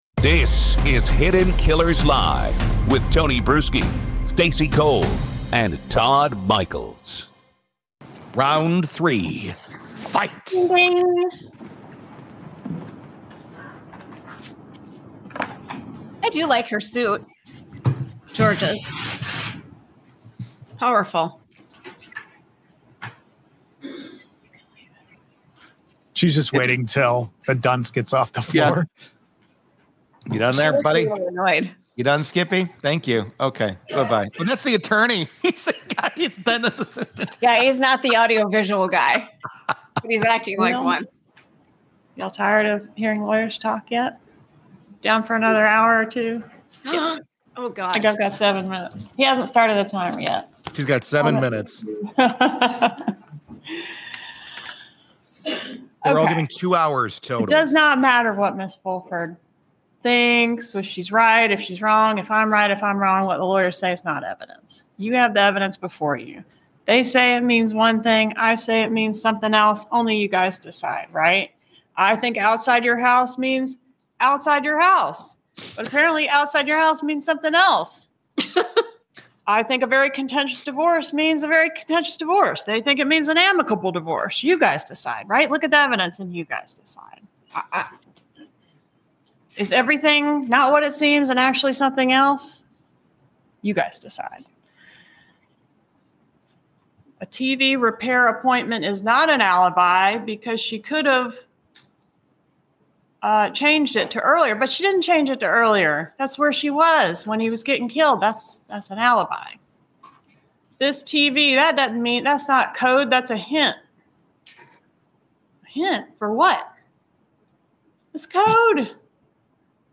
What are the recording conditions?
Watch Hidden Killers Live weekdays, 10AM–Noon CST, for real-time courtroom coverage, sharp analysis, and unfiltered panel reactions.